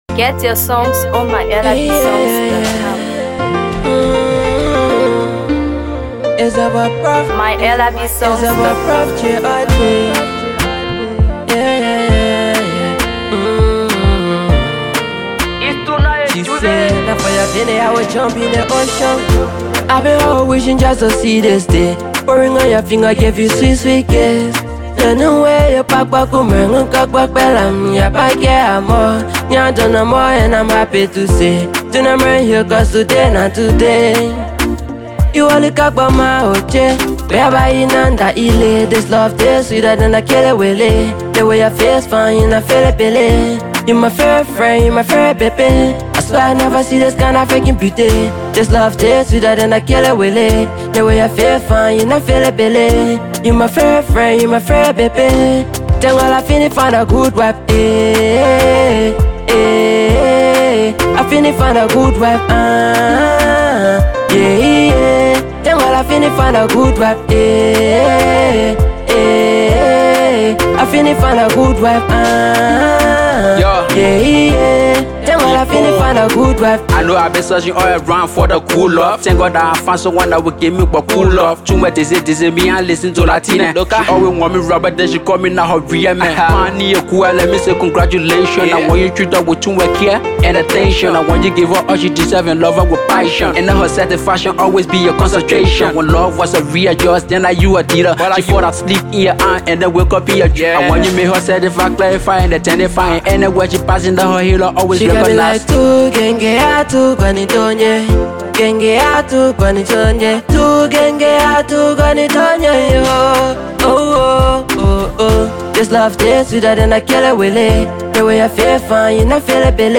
Afro Pop
feel-good anthem